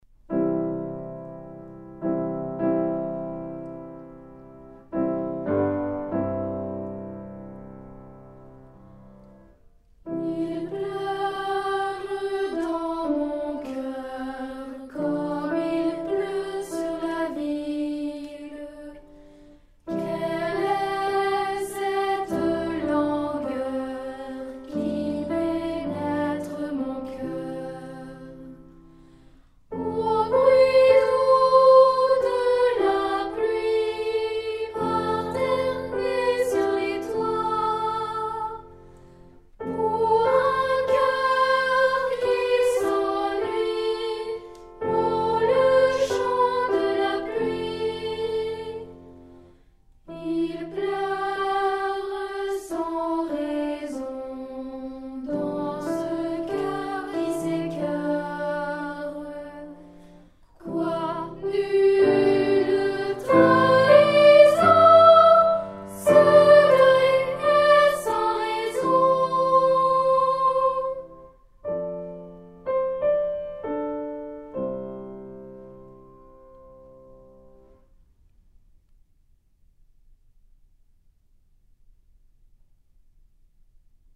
Enregistrement piano et voix
Arrangement Cyrille Lehn pour voix seule et accompagnement piano
nouveau-monde-2er-mvt-piano-chant.mp3